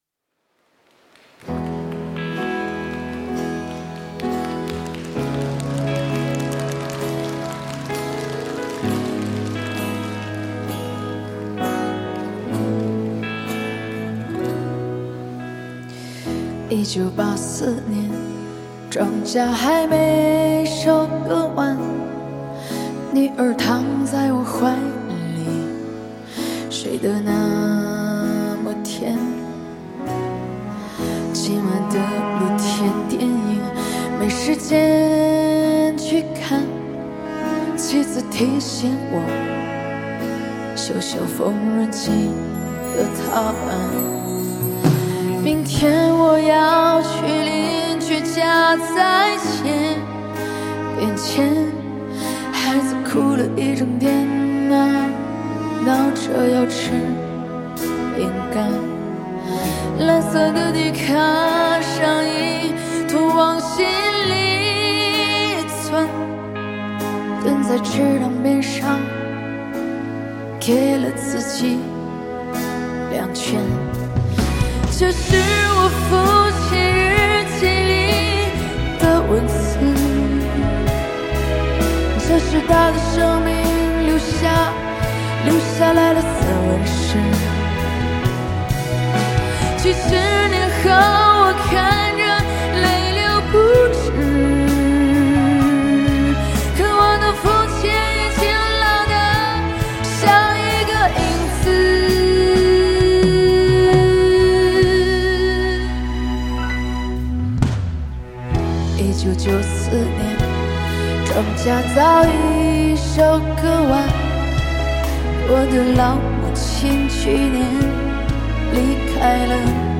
听了一下这个版本音质不错